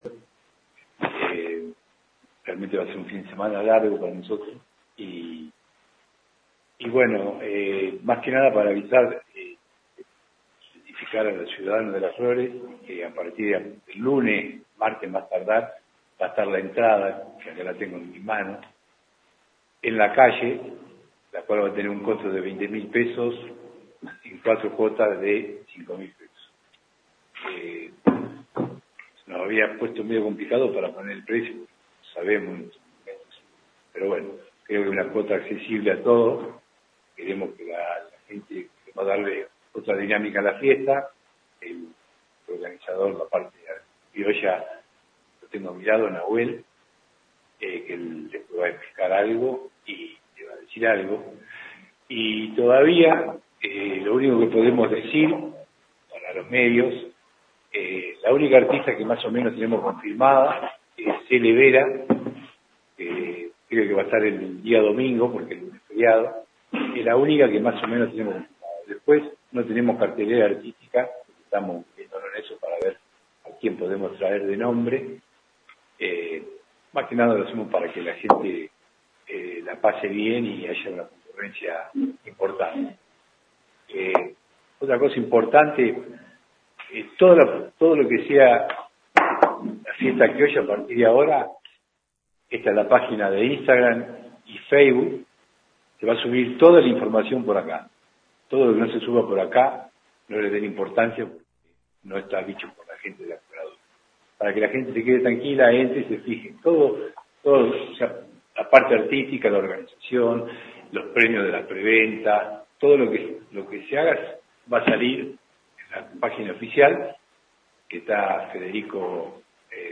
En conferencia de prensa que tuvo lugar en la mañana de este jueves en el SUM de la Asociación Cooperadora fue presentada oficialmente la 26ta. edición de la Fiesta del Hospital de Las Flores que se llevará a cabo los días 21, 22 y 23 de marzo en el campo de destrezas criollas del Centro Tradicionalista «La Tacuara».
Conferencia-Hospital.mp3